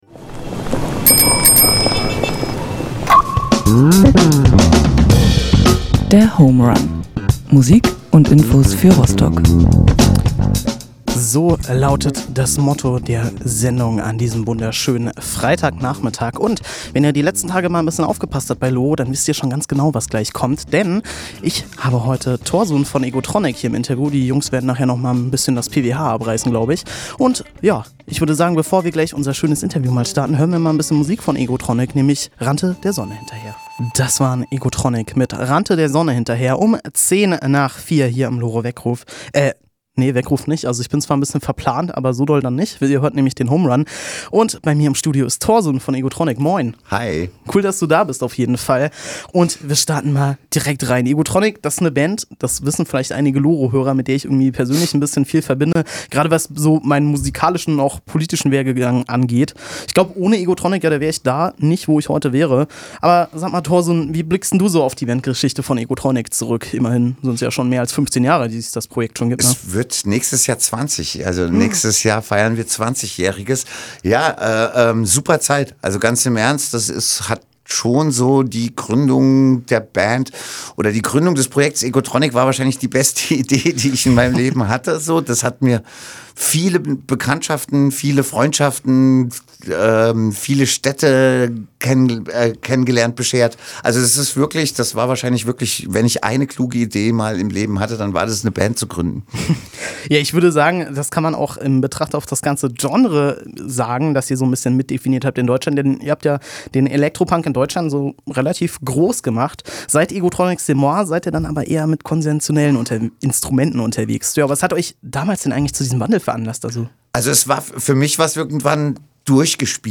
Das ganze Interview könnt ihr hier ohne Musik nachhören:
Egotronic-Interview.mp3